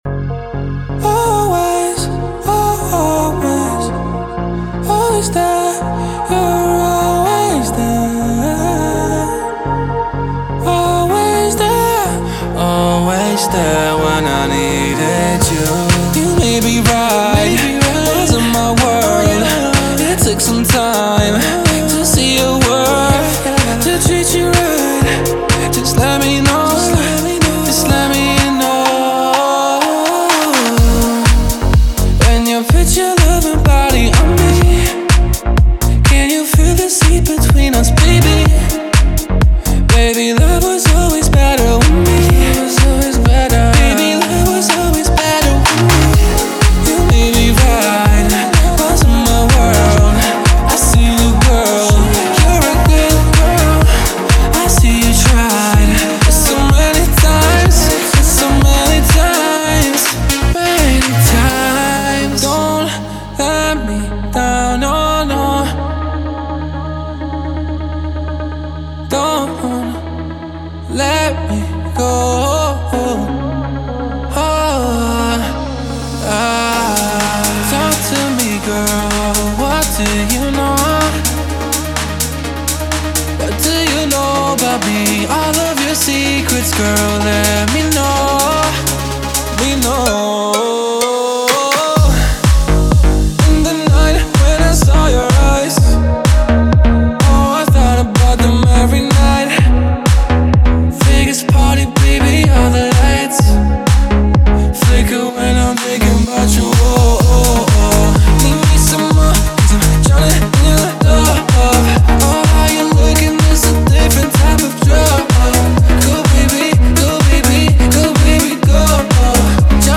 Genre:Deep House
豊かで変化するパッド、深みのある共鳴ベース、鮮やかなリード、汎用性の高いプラックまで、多彩なサウンドを提供します。
デモサウンドはコチラ↓
5 Vocal Kits (82 Total Files)